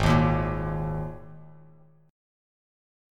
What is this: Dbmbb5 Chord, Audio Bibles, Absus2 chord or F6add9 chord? Absus2 chord